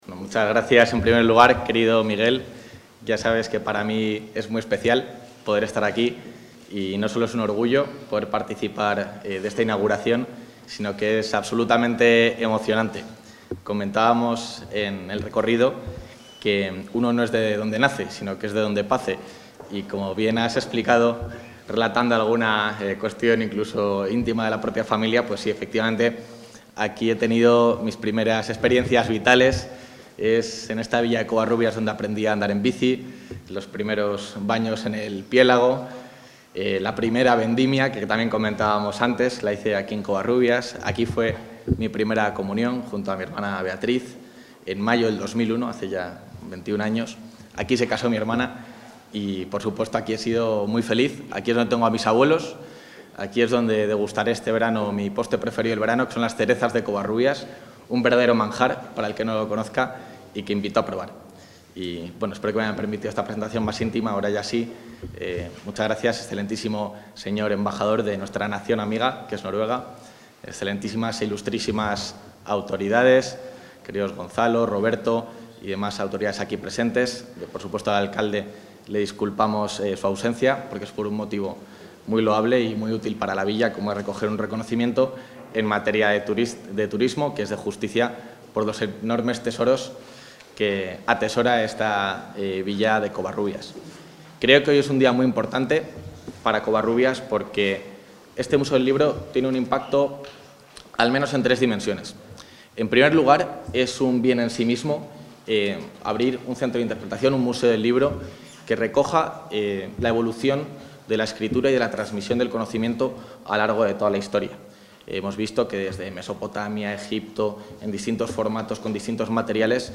Material audiovisual de la asistencia del vicepresidente de la Junta a la inauguración del Centro de Interpretación ‘Museo del Libro’ de Covarrubias
Intervención del vicepresidente de la Junta.